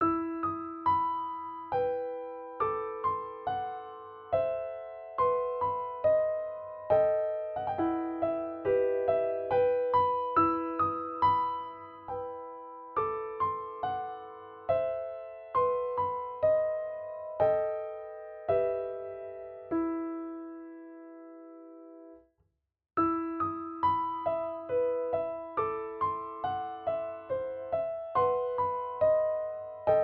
Does Not Contain Lyrics
E Minor
Andante